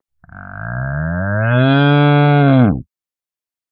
モーォ。
牛の鳴き声-02 着信音